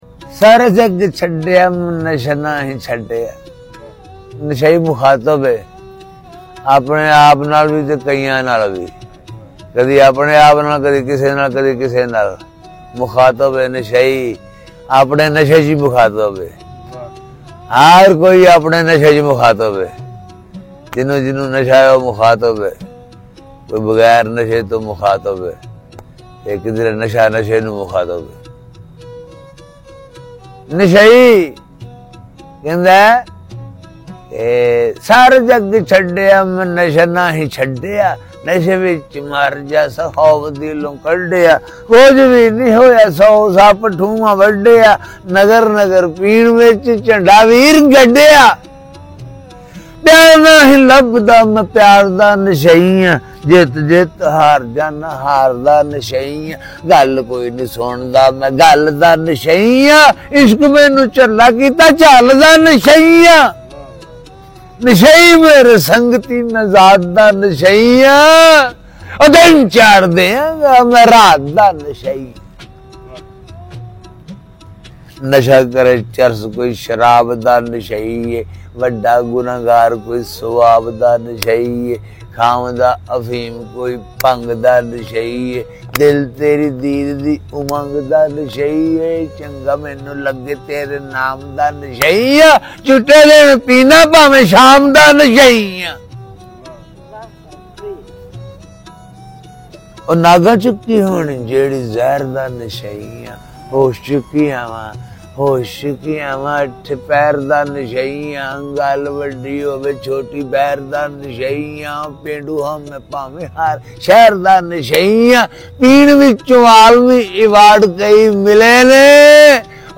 ویر سپاہی Nashei Aan Full Nazam Latest Famous Top Trending Punjabi Poetry WhatsApp Status 2025 Poet.